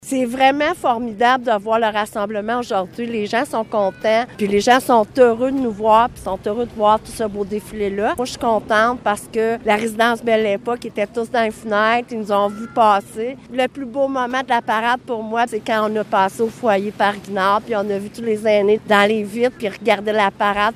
La mairesse de Maniwaki, Francine Fortin, ne cache pas sa joie relativement au succès obtenu :